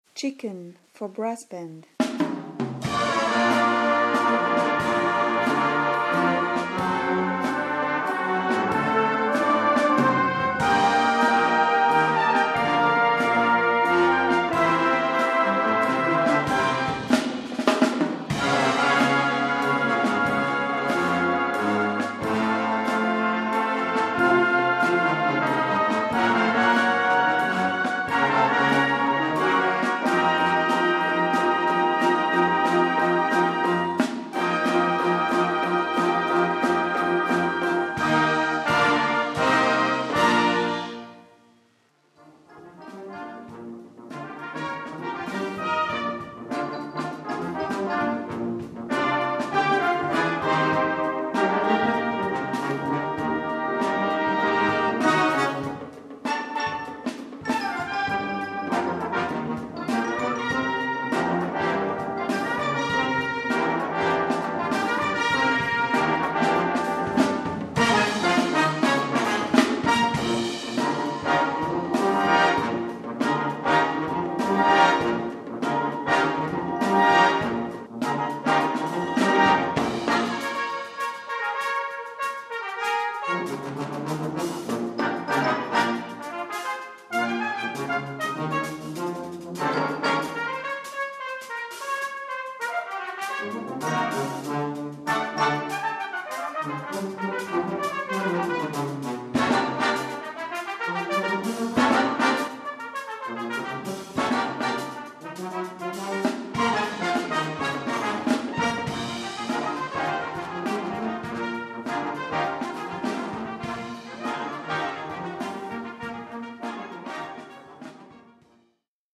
Brass Band
Jazz / Swing / Ragtime / Blues